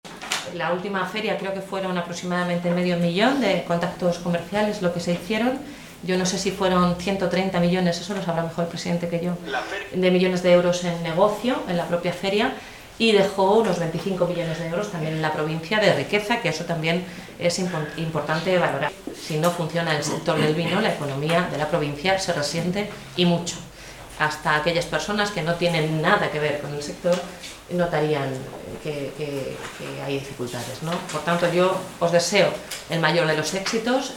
Rueda de prensa en el interior de FENAVÍN
Delegada-provincia-de-Ciudad-Real-BLANCA-FERNANDEZ.mp3